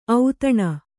♪ autaṇa